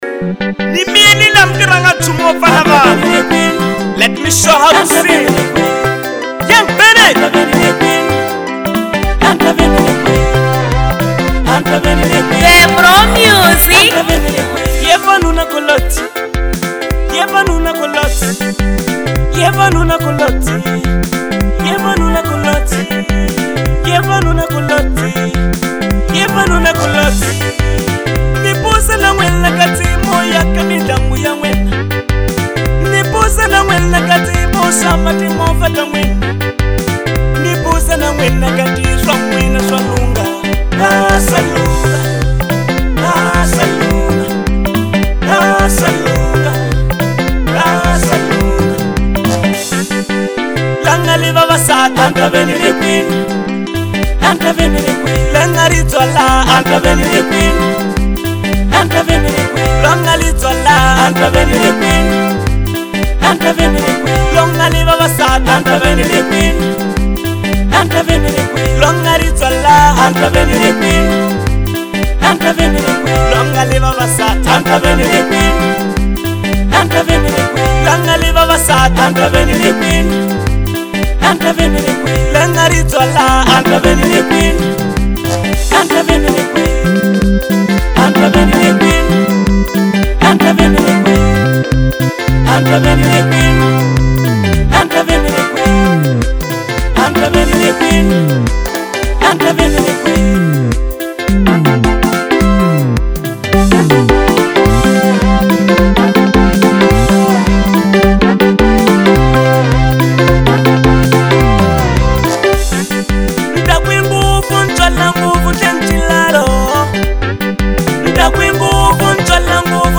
fusionar elementos tradicionais e contemporâneos